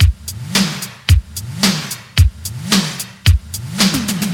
• 110 Bpm Drum Groove F Key.wav
Free breakbeat sample - kick tuned to the F note. Loudest frequency: 2191Hz
110-bpm-drum-groove-f-key-86T.wav